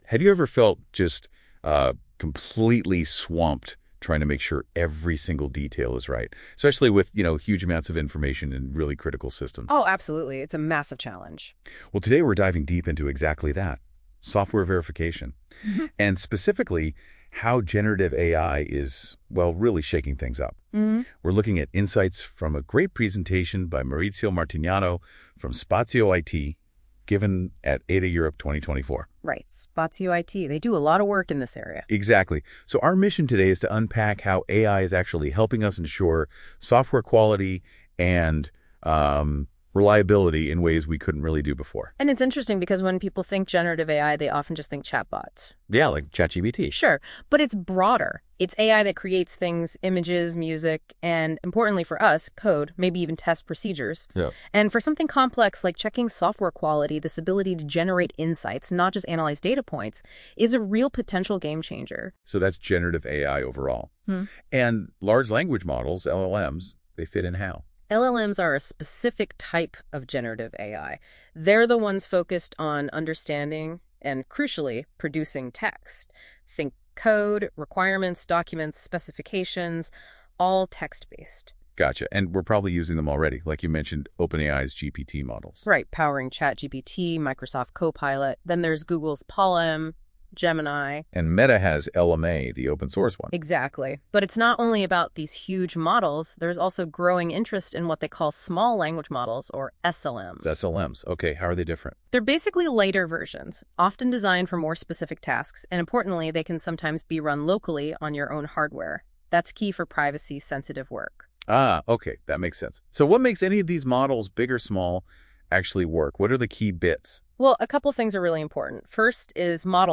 accompanying audio has generated using Google Gemini.